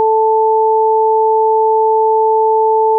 wav_stereo_44k_mix_sample.wav